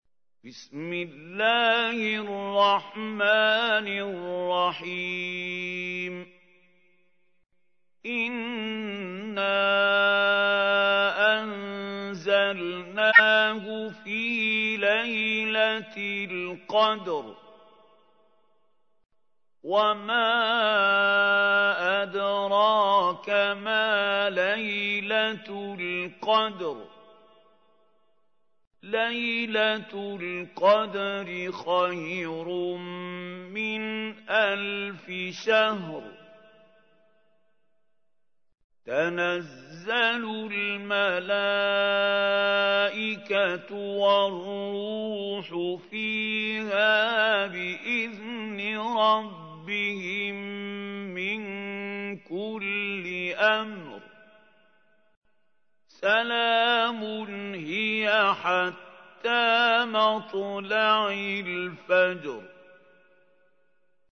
تحميل : 97. سورة القدر / القارئ محمود خليل الحصري / القرآن الكريم / موقع يا حسين